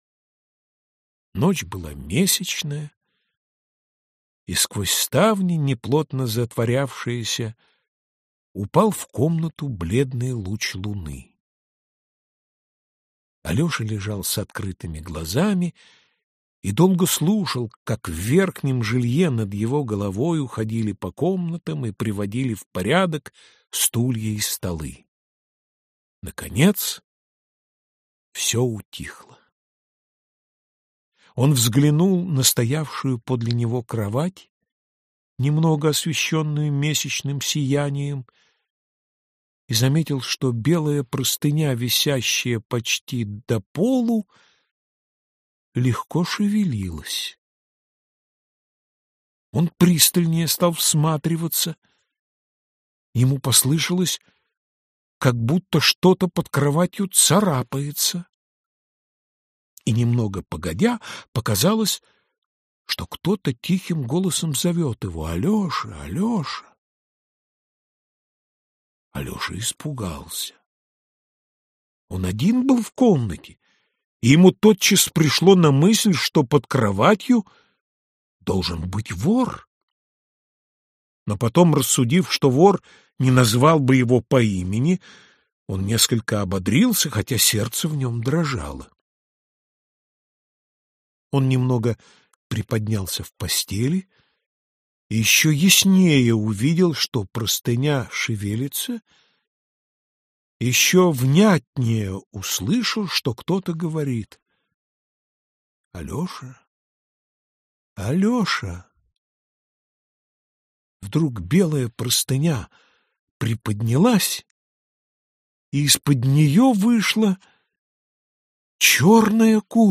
Аудиокнига Черная курица, или Подземные жители | Библиотека аудиокниг